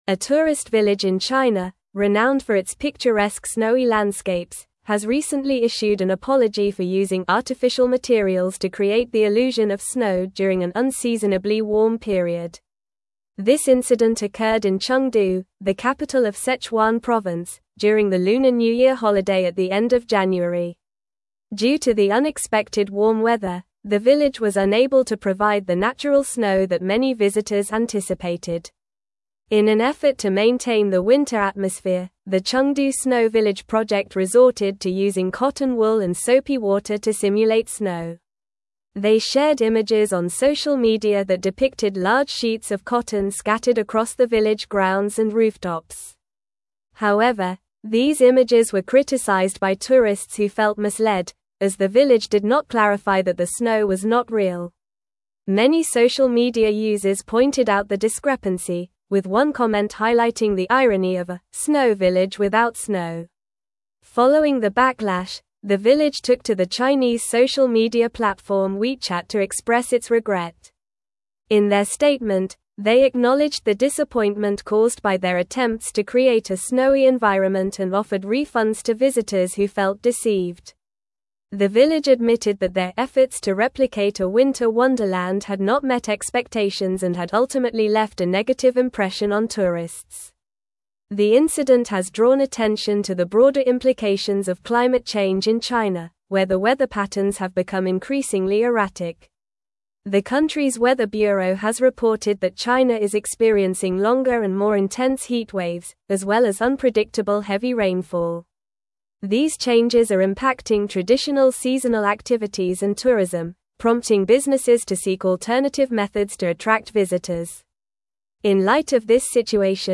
Normal
English-Newsroom-Advanced-NORMAL-Reading-Chengdu-Village-Apologizes-for-Fake-Snow-Misleading-Tourists.mp3